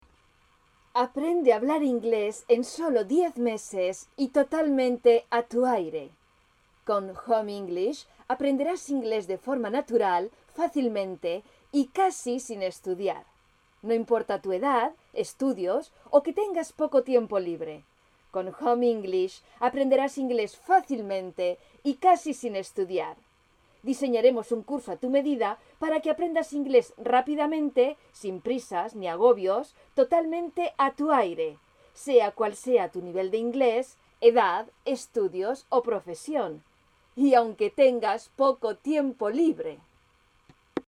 voix off / doublage / publicité / audio-guide (espagnol - catalan - français) Audio Pigment